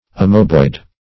Amoebiform \A*m[oe]"bi*form\, Amoeboid \A*m[oe]"boid\, a.